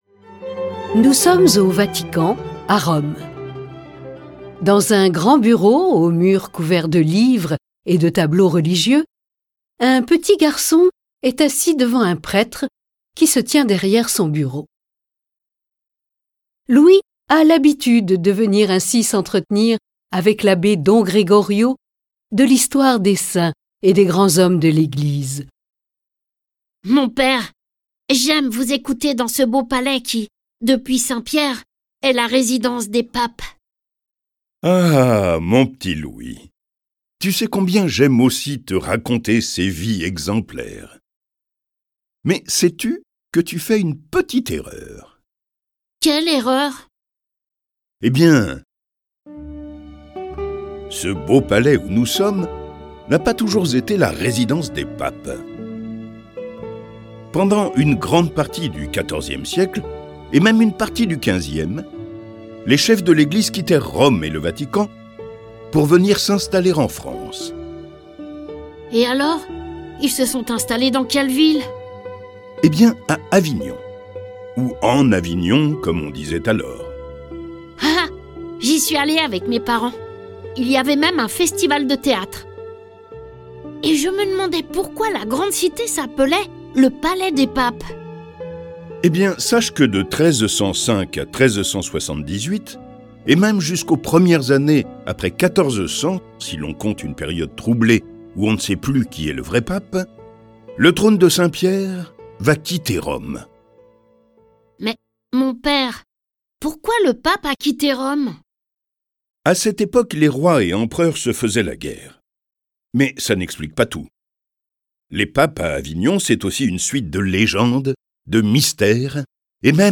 Le récit de la papauté d'Avignon est animé par 7 voix et accompagné de près de 40 morceaux de musique classique.